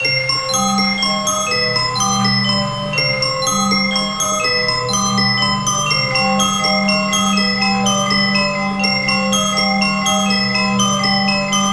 Gloomy Musicbox_Broken Musicbox.wav